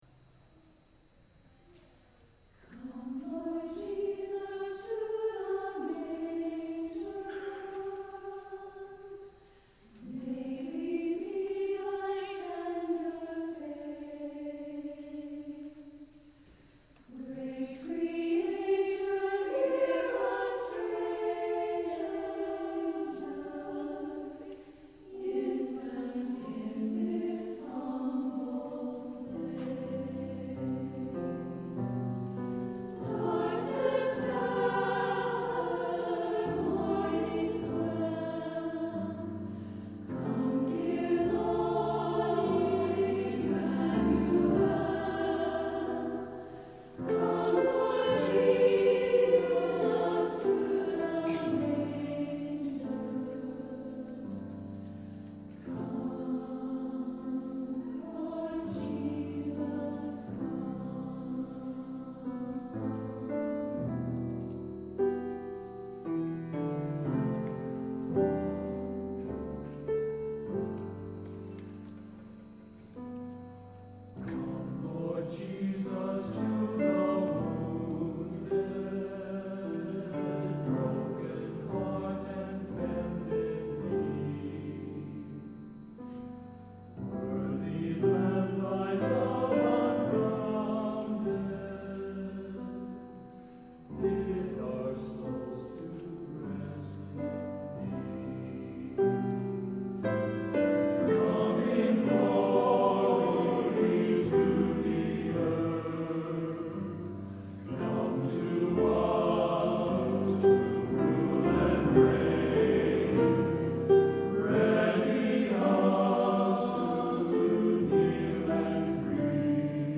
SATB and Piano Also available for TTBB Difficulty Level